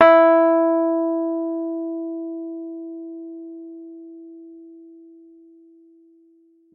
piano-sounds-dev
e3.mp3